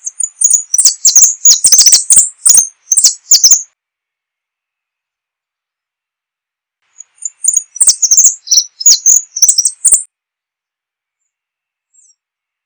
Sicalis flaveola - Dorado